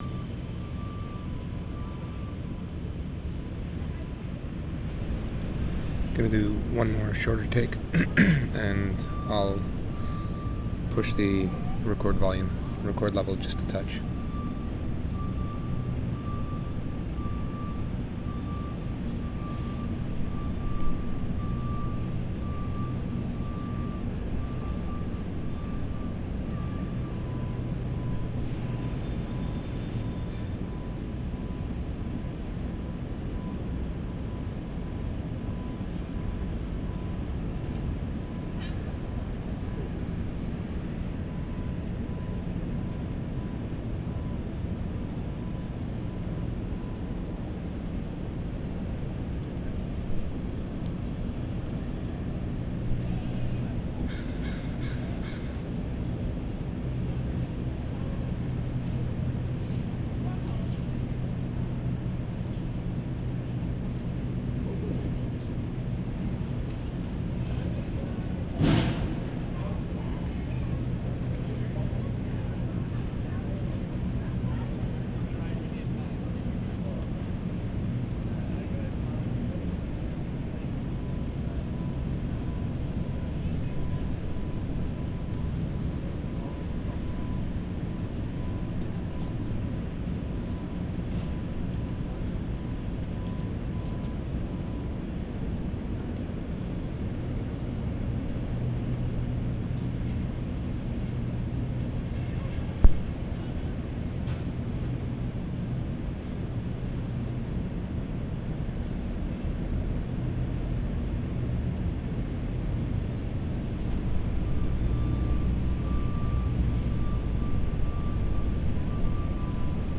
Student Union Building II, 3:31
7. Same position as previous recording, begins with truck in reverse - beeping, bird chirp at 0:54, crash at 1:09, truck engine at 1:54, voices at 2:30, horn at 3:20.